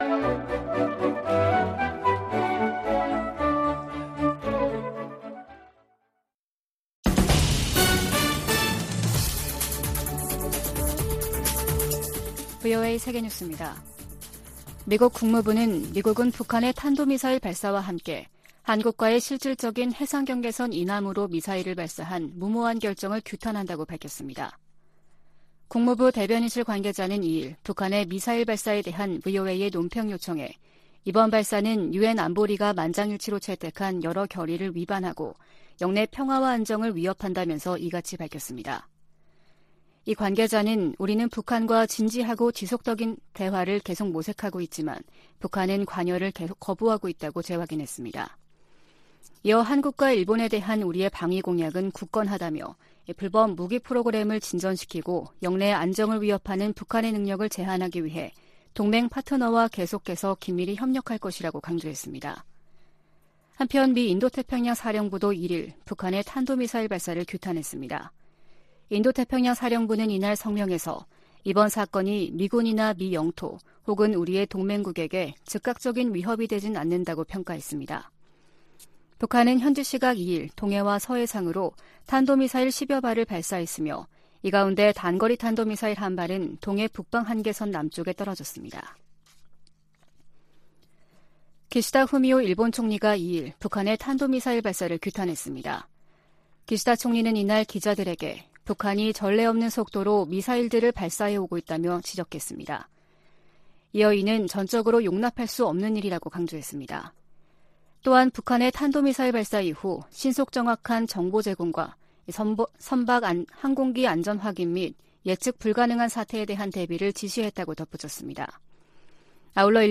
VOA 한국어 아침 뉴스 프로그램 '워싱턴 뉴스 광장' 2022년 11월 3일 방송입니다. 북한 정권이 남북 분단 이후 처음으로 동해 북방한계선 NLL 이남 한국 영해 근처로 탄도미사일을 발사하는 등 미사일 수십 발과 포병 사격 도발을 감행했습니다.